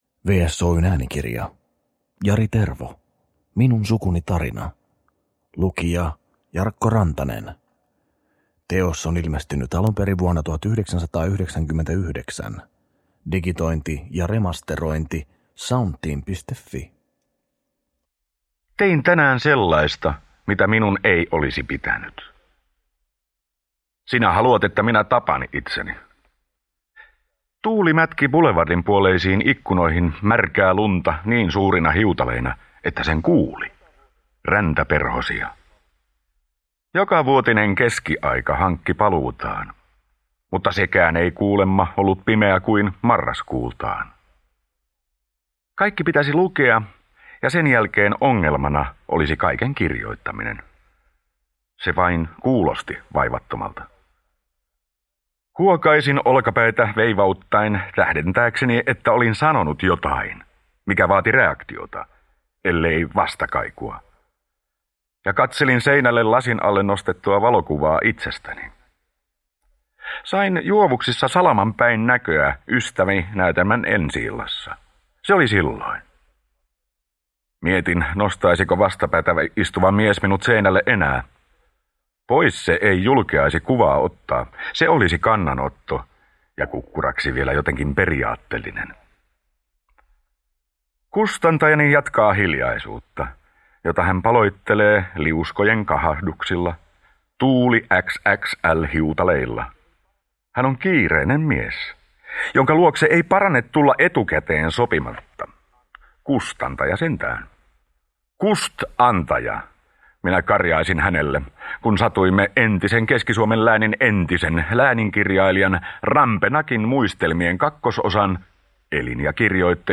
Minun sukuni tarina – Ljudbok – Laddas ner